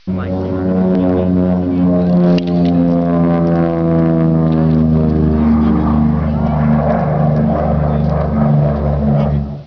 The Allison 1710 was a liquid cooled inline engine with 12 cylinders, developing 1250 horsepower.
p-38.wav